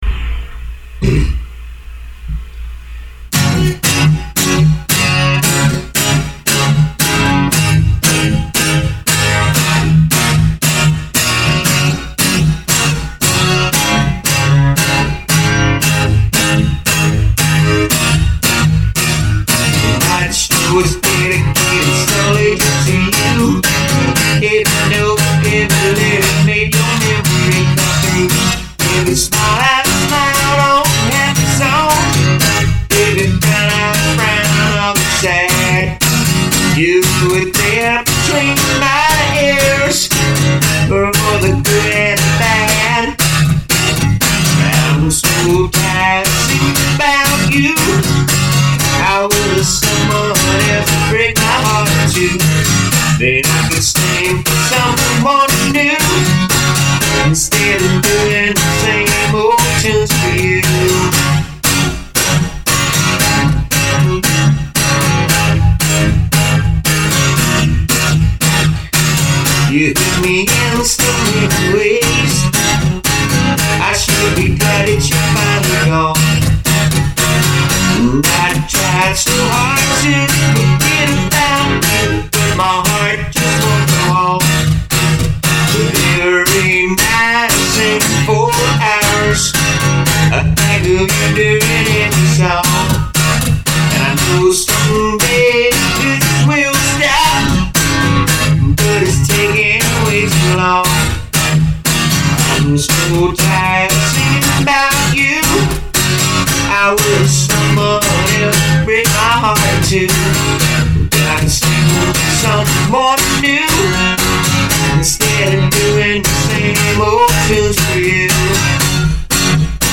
Country
Folk
Country-rock